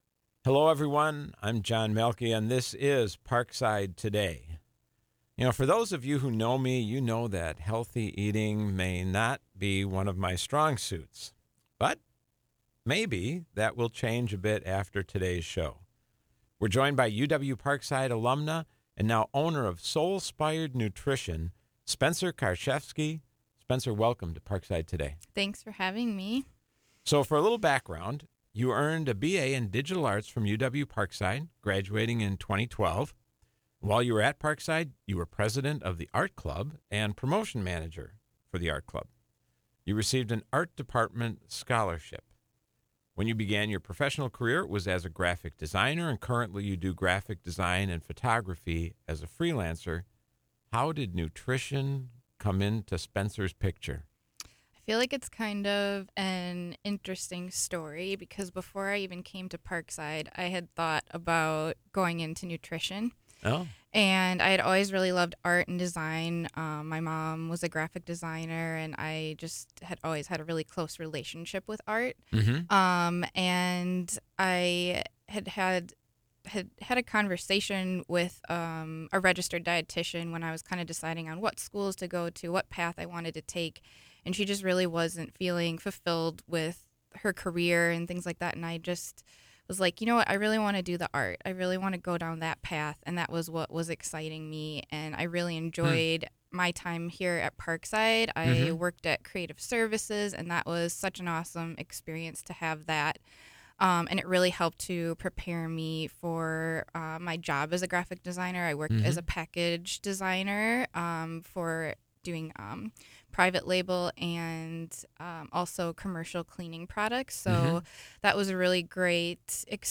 This show originally aired on Tuesday, June 18, at 4 p.m. on WIPZ 101.5 FM.